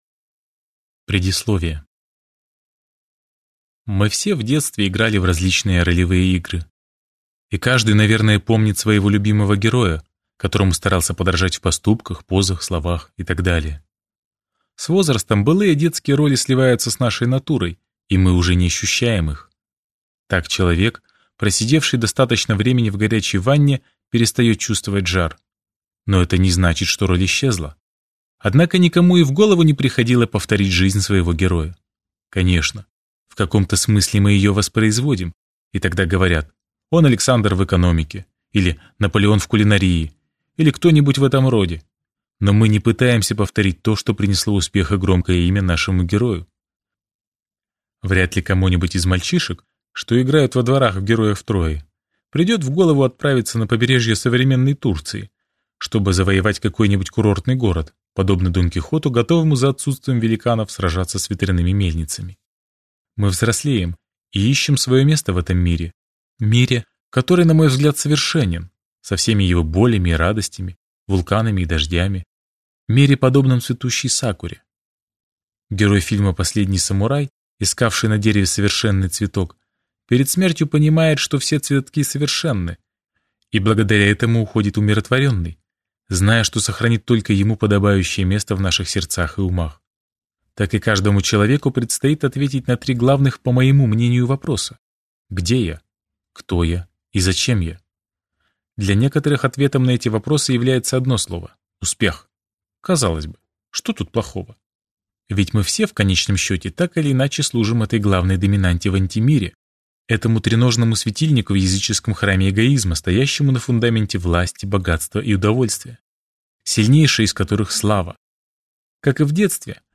Аудиокнига Сорок пятый километр | Библиотека аудиокниг